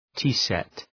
Shkrimi fonetik {‘ti:set}